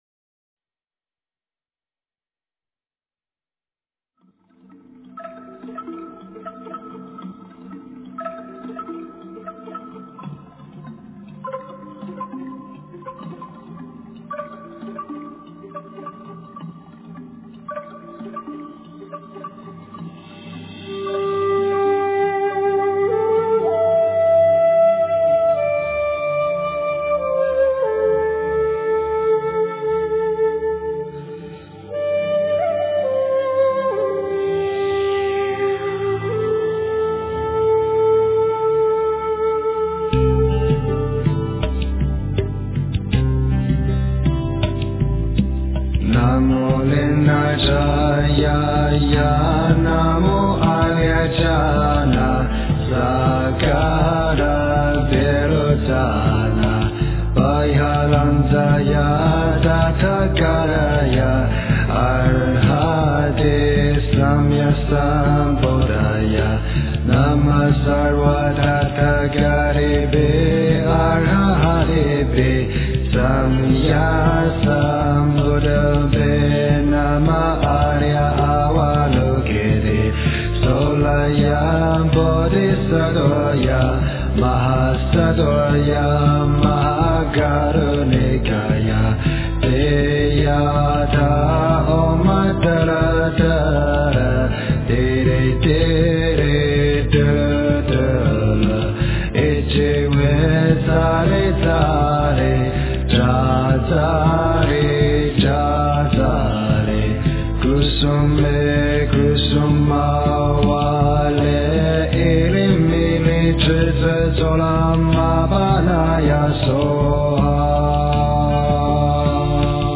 诵经
佛音 诵经 佛教音乐 返回列表 上一篇： 般若波罗密多心经-梵唱 下一篇： 般若波罗蜜多心经 相关文章 清心快乐颂-古筝--妙莲华唱片 清心快乐颂-古筝--妙莲华唱片...